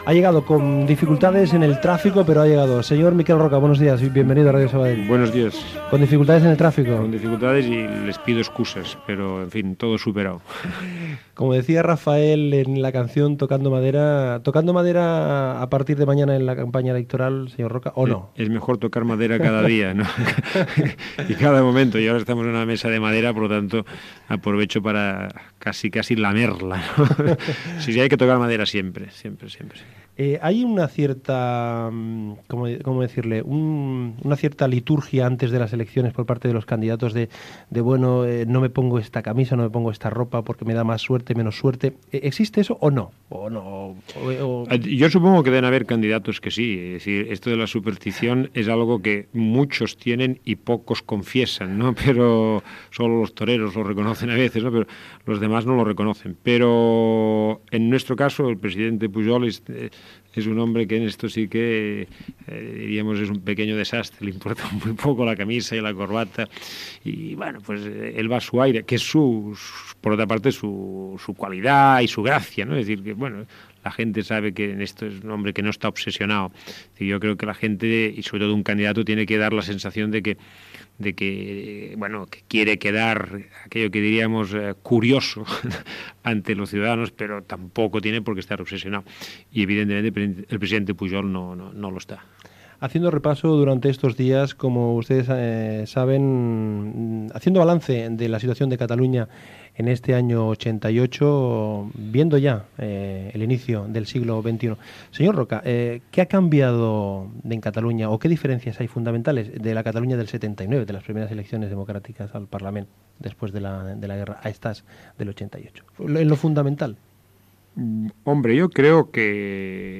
Entrevista al polític Miquel Roca i Junyent que a l'endemà començarà la campanya electoral.
Entreteniment